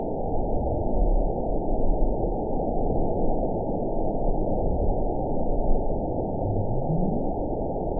event 911219 date 02/18/22 time 04:28:00 GMT (3 years, 3 months ago) score 9.39 location TSS-AB04 detected by nrw target species NRW annotations +NRW Spectrogram: Frequency (kHz) vs. Time (s) audio not available .wav